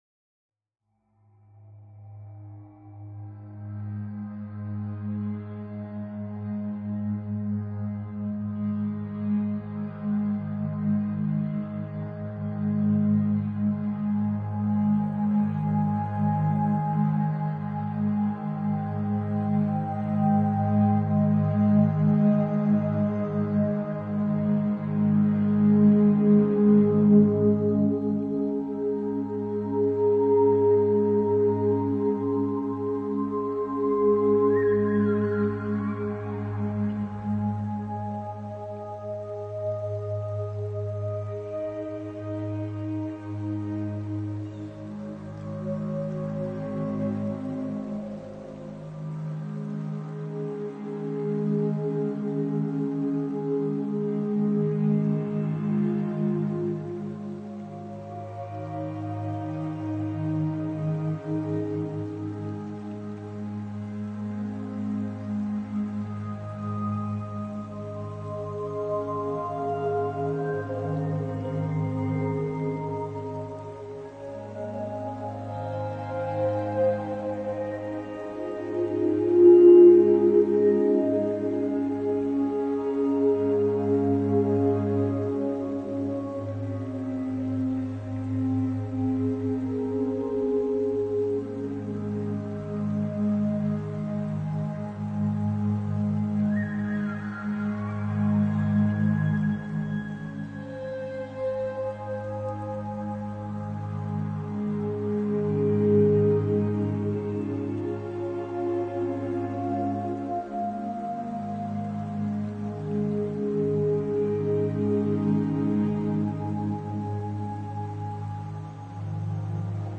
描述：9:40分钟的冥想创作，带有新时代和整体声音。 戏剧性的，梦幻般的，宽敞的。
催眠具有一种奇怪的意识和认知感。 一种漂浮在空中或迷失自我、漂浮的感觉。